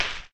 default_dig_crumbly.1.ogg